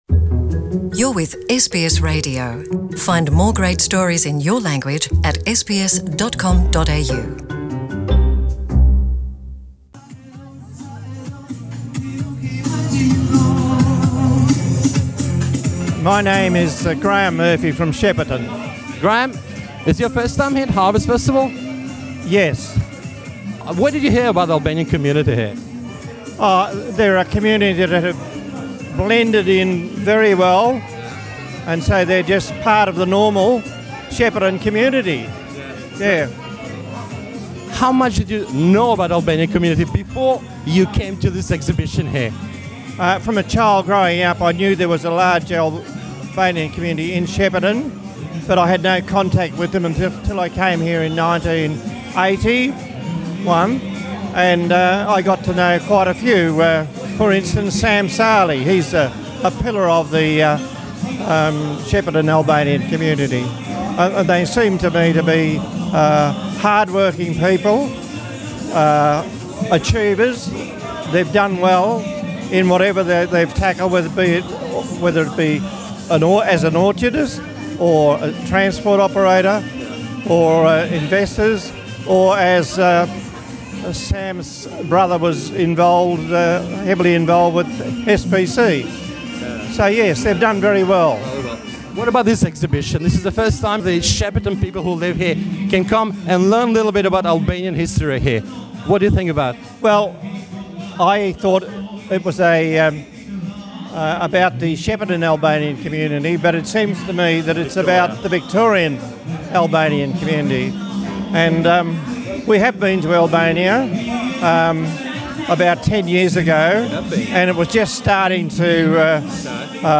We interviewed one of the attendees